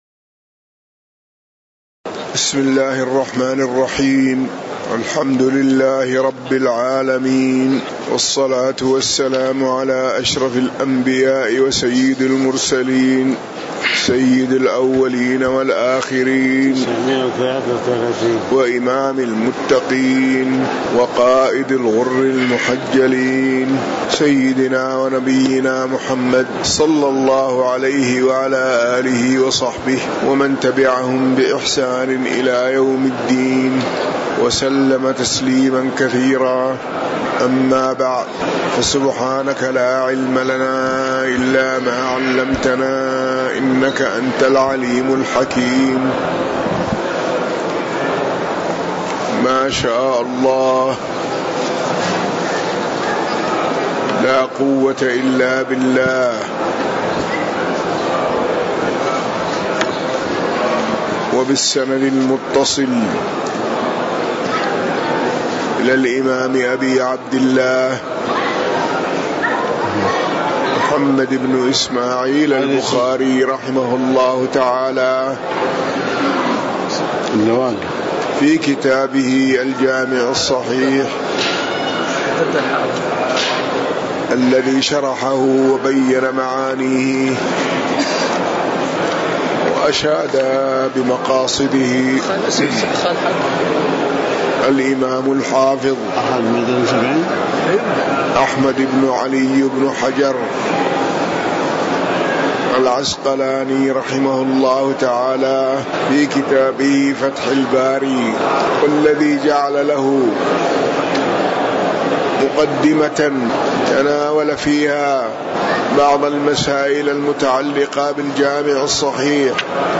تاريخ النشر ٢١ شوال ١٤٤٠ هـ المكان: المسجد النبوي الشيخ